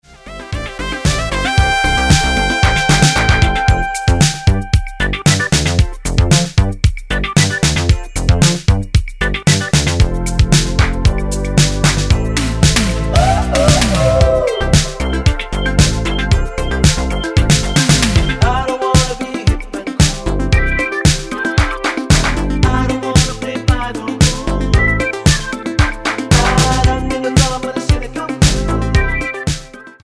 --> MP3 Demo abspielen...
Tonart:C mit Chor